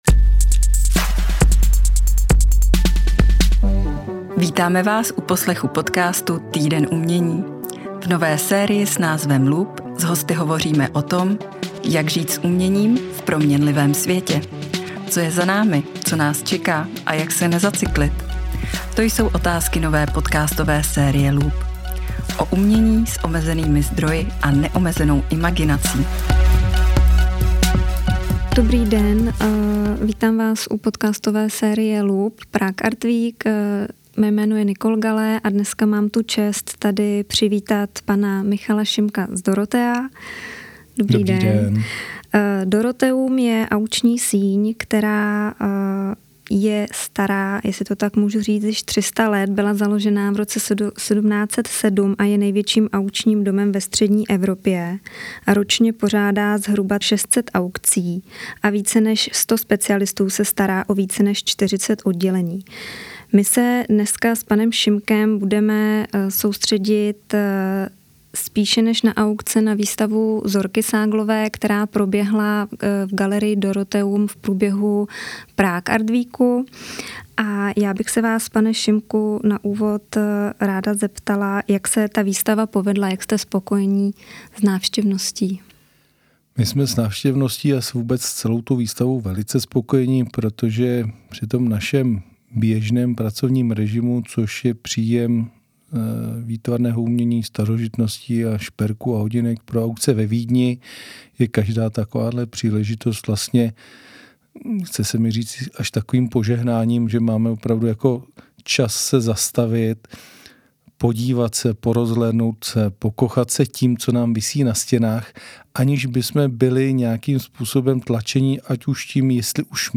V rozhovoru